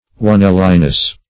Oneliness \One"li*ness\, n.